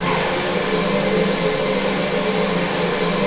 ATMOPAD14 -LR.wav